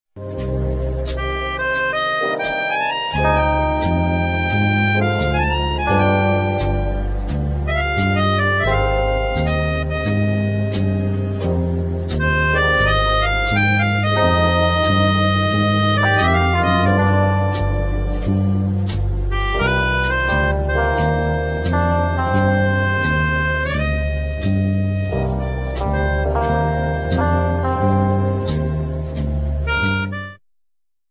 relaxed medium instr.